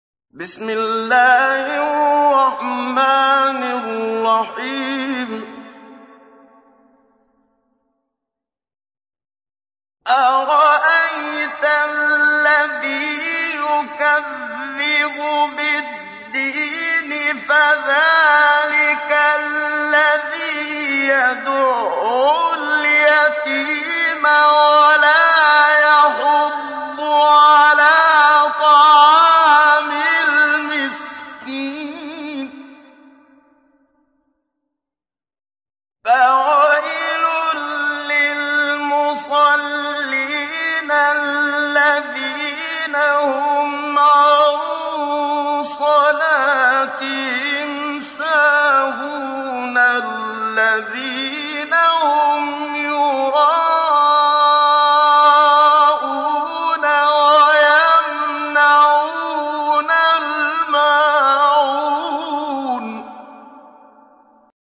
تحميل : 107. سورة الماعون / القارئ محمد صديق المنشاوي / القرآن الكريم / موقع يا حسين